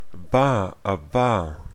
Plosives p b p
Voiced_labiodental_plosive.ogg.mp3